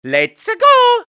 One of Mario's voice clips in Mario Kart 7